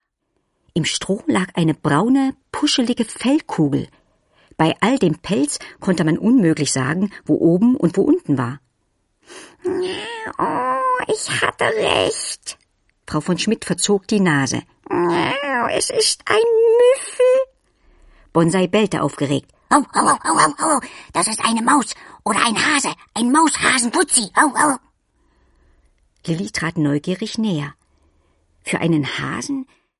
Liliane Susewind - Ein kleines Reh allein im Schnee | tiptoi® Hörbücher | Ravensburger